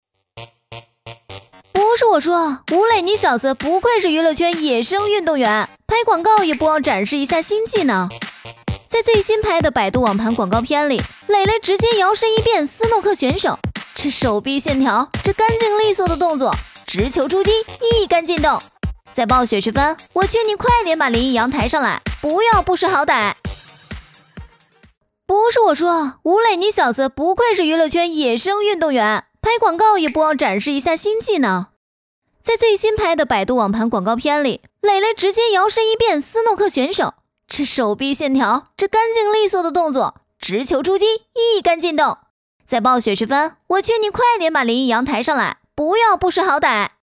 女3-综艺感-网盘升级.mp3.mp3